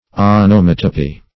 Onomatopy \On`o*mat"o*py\, n.